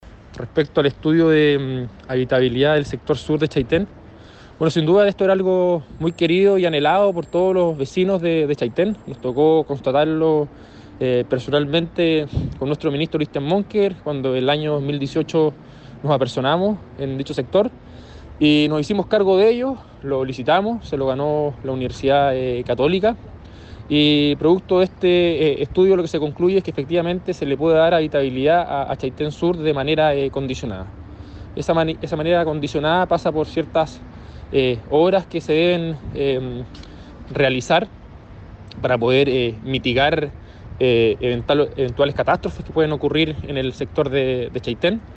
Esta buena noticia significará entonces un resurgimiento de esta zona, según comentó el seremi de Vivienda y Urbanismo, Rodrigo Wainraight, quien dijo que esto fue posible gracias a las conclusiones de un estudio de la Universidad Católica de Chile, encargada por el Ministerio.